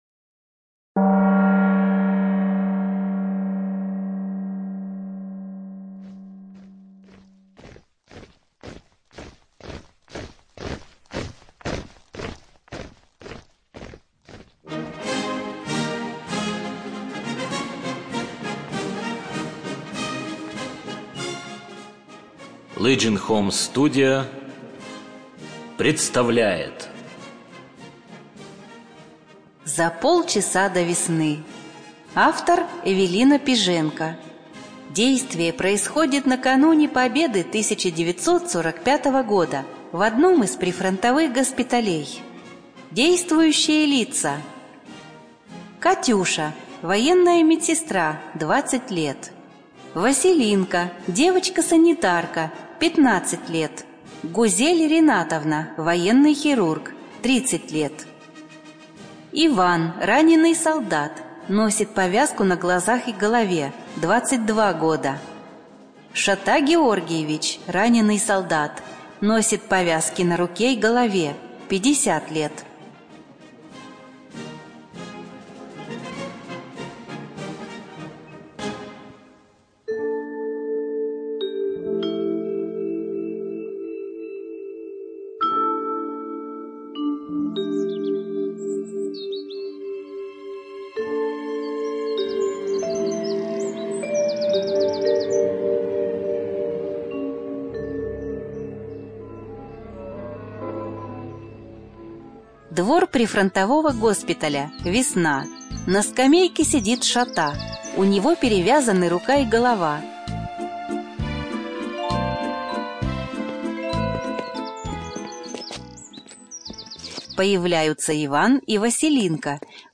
Студия звукозаписисемья